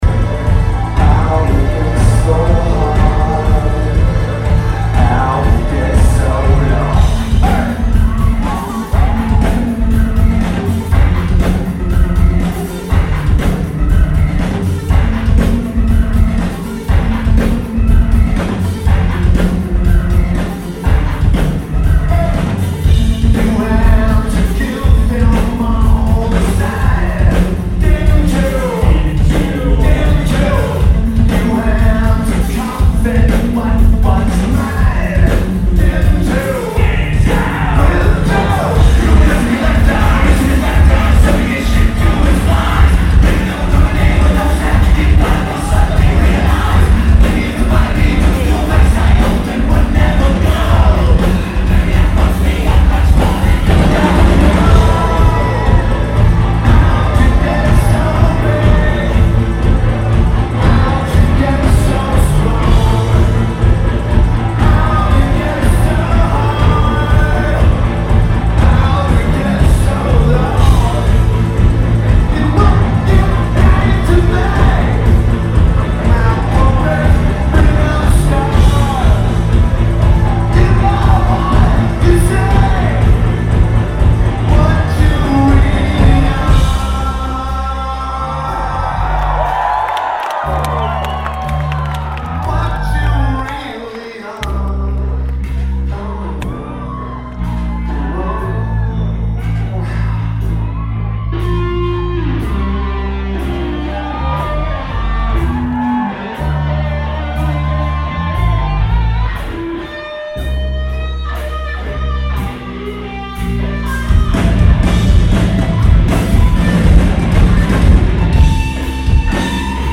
Zepp
Osaka Japan
Lineage: Audio - AUD, Unknown Audience Recording
Sound is pretty good too.